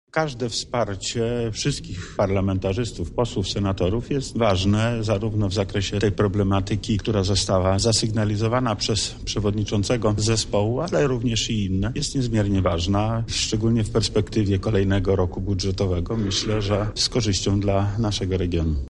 • mówi Wojewoda Lubelski, Lech Sprawka.